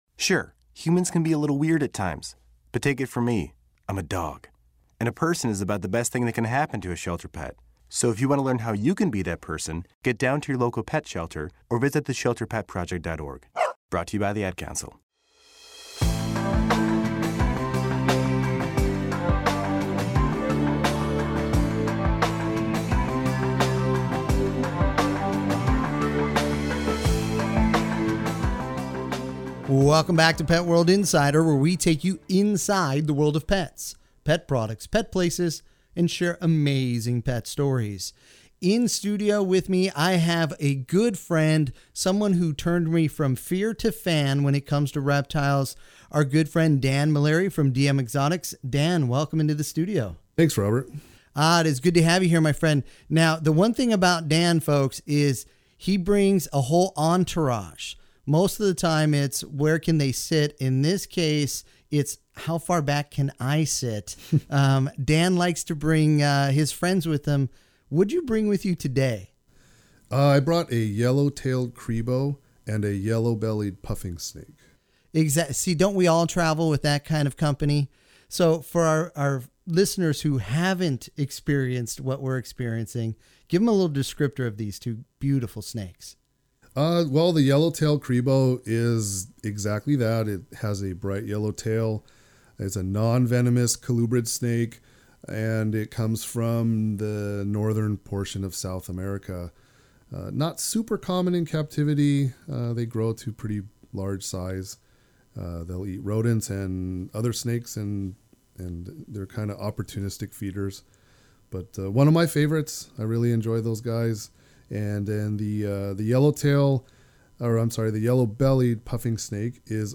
Enjoy this Pet World Radio Segment in case a station near you does not currently carry Pet World Radio on the EMB or CRN networks!